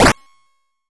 hitsound.wav